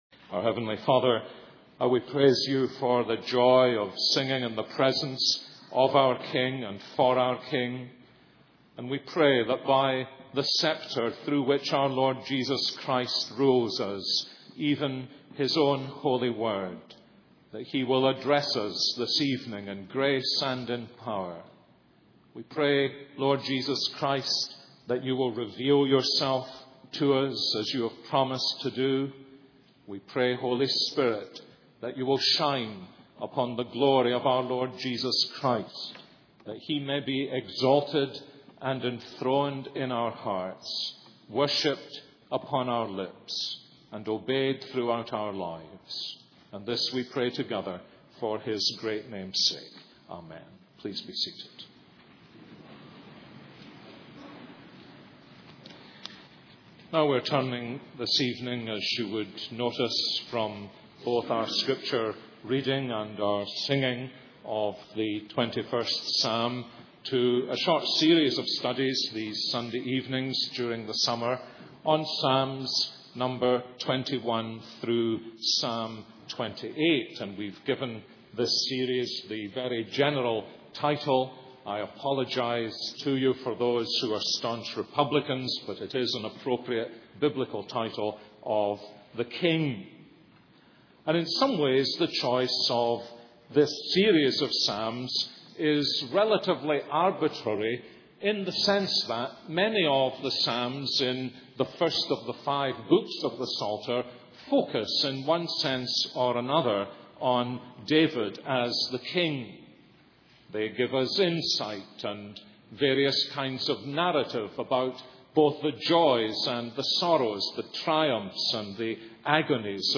This is a sermon on Psalm 21.